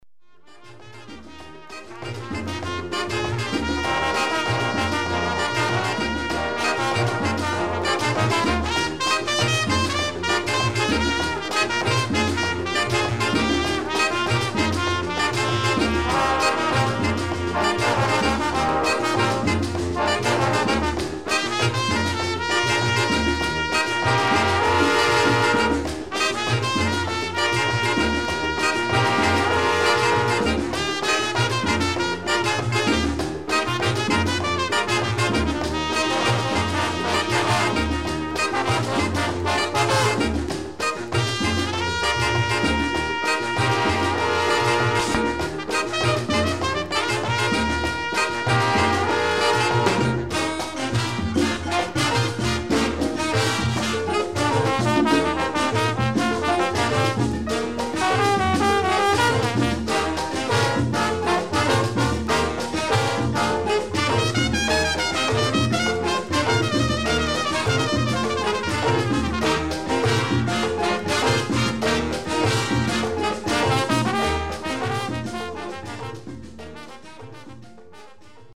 1970年代当時のキューバ音楽のコンピレーション